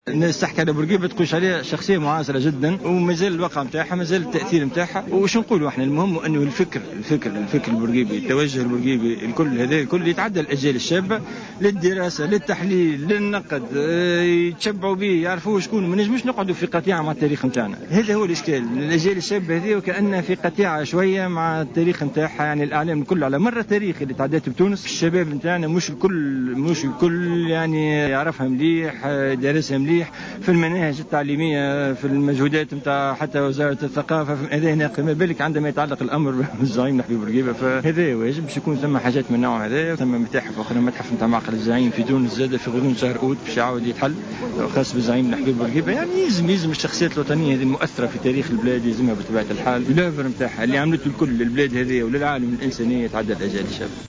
المنستير : وزيرا الثقافة والتعليم العالي يشرفان على إحياء الذكرى 111 لميلاد الزعيم بورقيبة